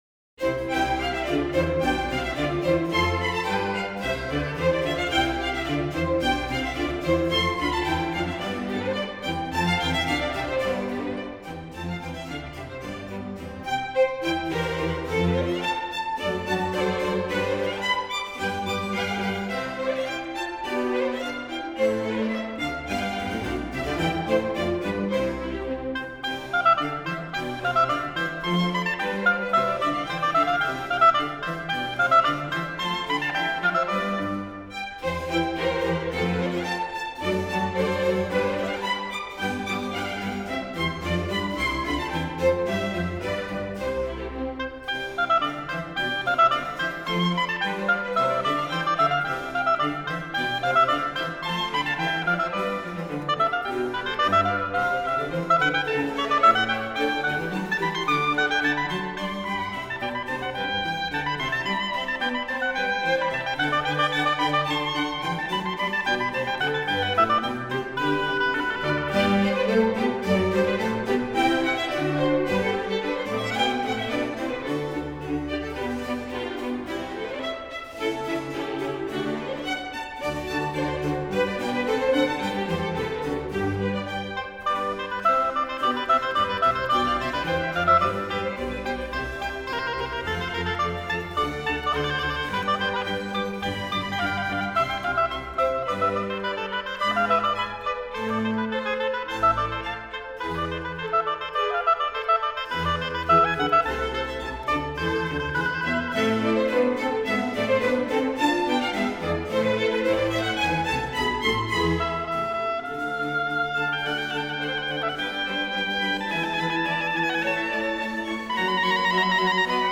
音乐类型:  古典